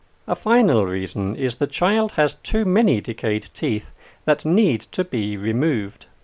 DICTATION 8